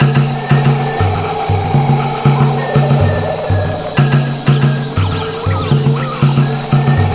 junglemix.wav